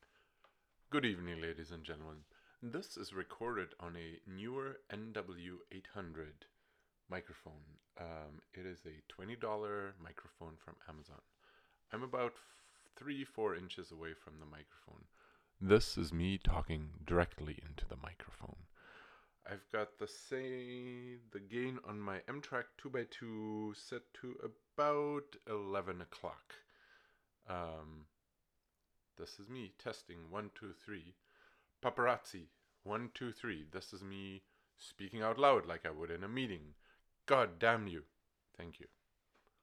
All were mounted on a microphone arm.
Note that the recordings are all mono (left ear) because my audio interface sends input one to the left channel.
Neewer NW-800XLR condenser microphone